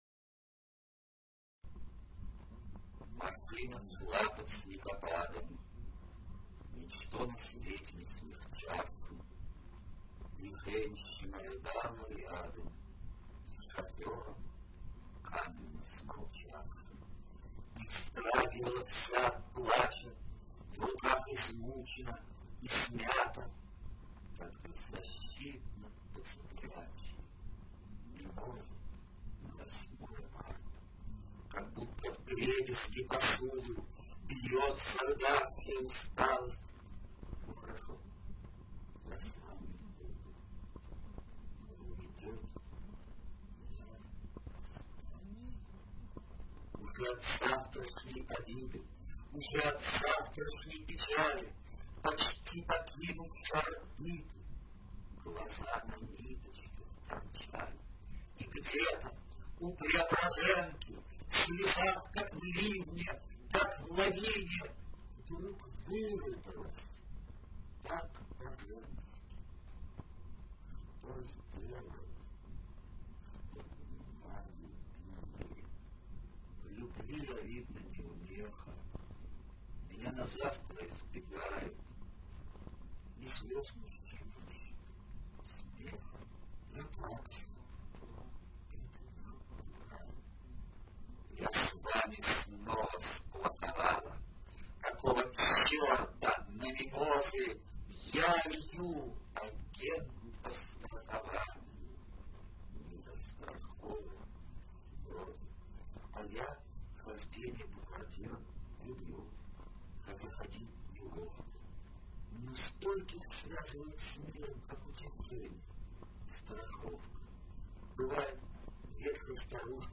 Манера чтения уж очень похожа.
Евтушенко...тембр голоса другой совсем:)Но-спори ть не буду:)